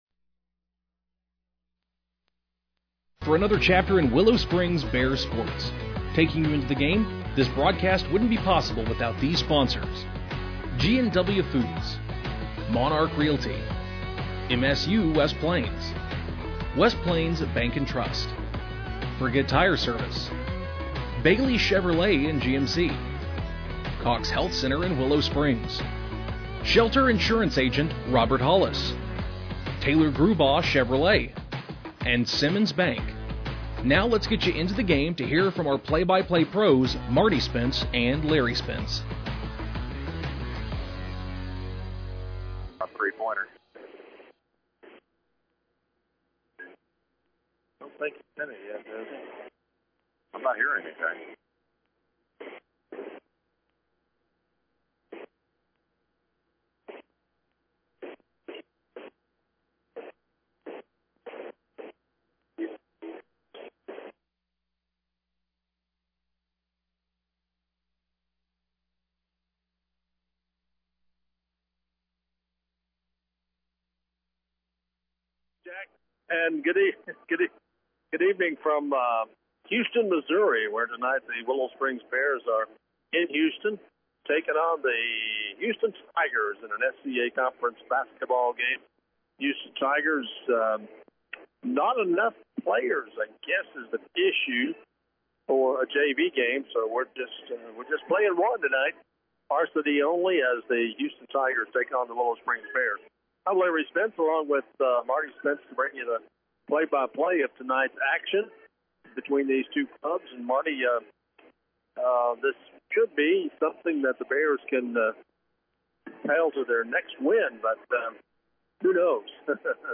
Game Audio Below: Houston won the tip to get the game under way.